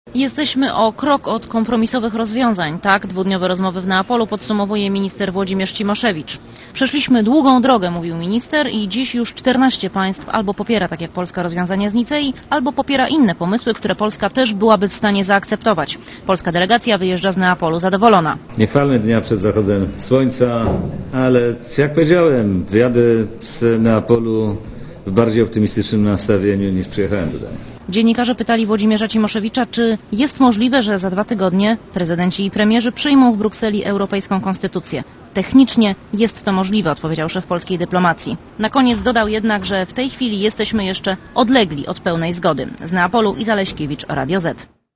Korespondencja Radia Zet z Neapolu (176Kb)